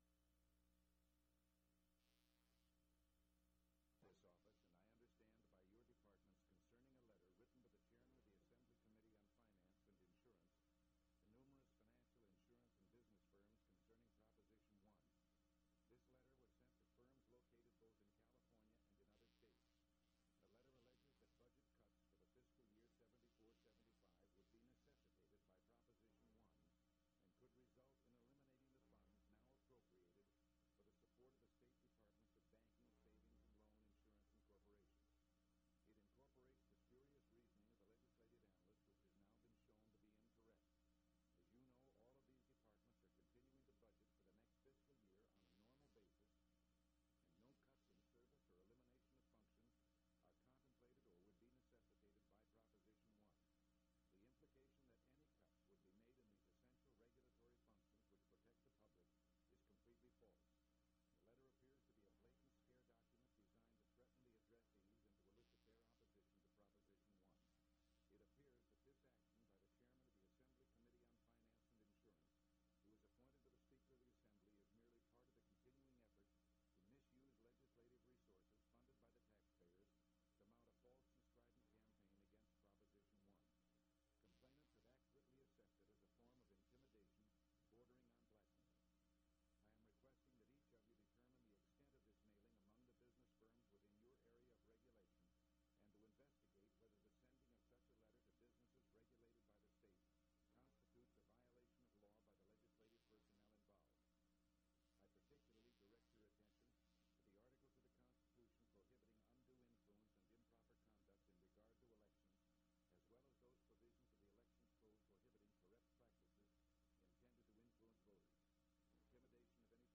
Governor Ronald Reagan News Conference #3
Audio Cassette Format.